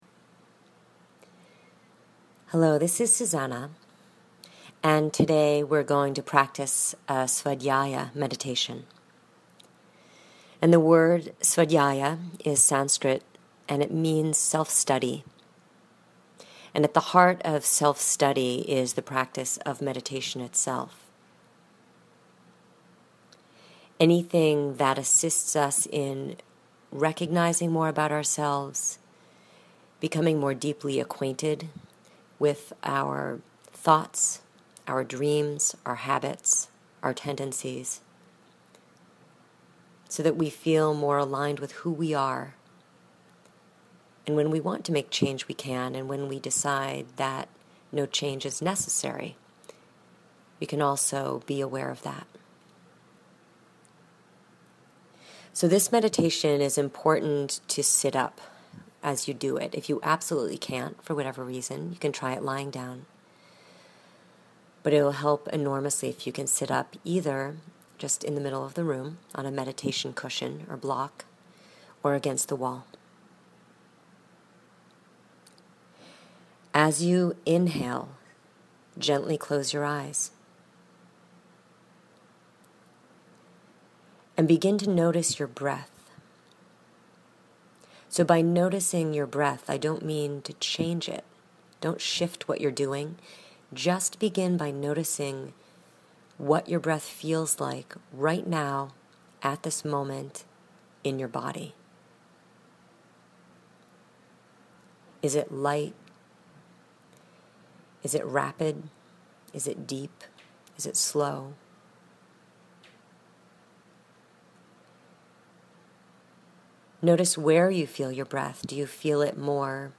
Here is the Week 2 Meditation: Svadhyaya